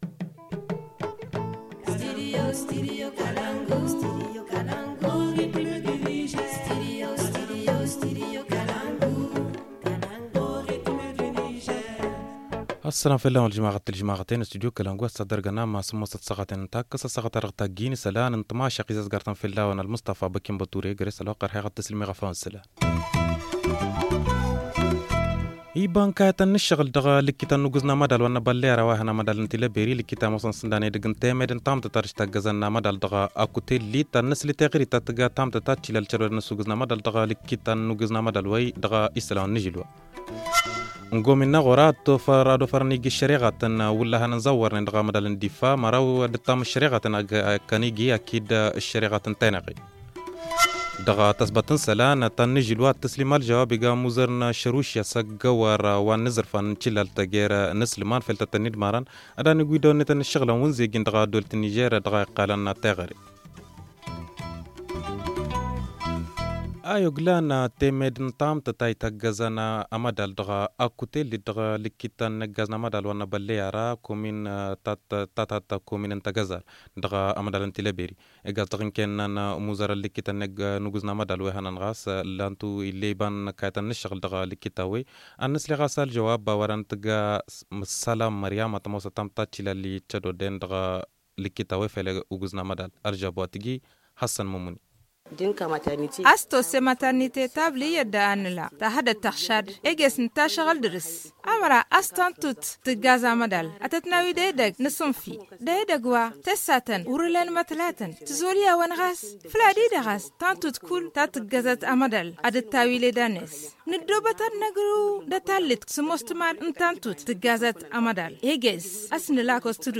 Le journal du 15 janvier 2020 - Studio Kalangou - Au rythme du Niger